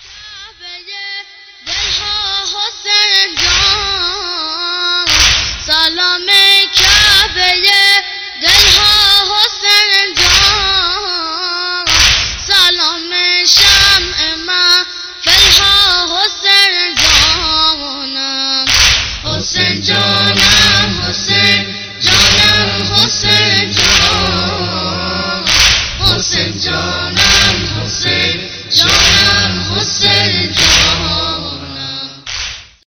Islamic Ringtones